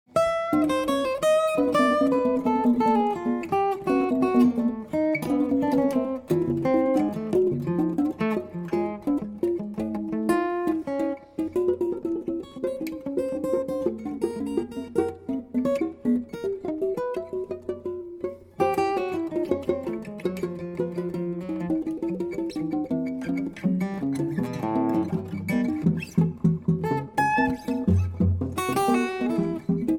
Two Master Guitarists + 18 tracks = Acoustic Improv Heaven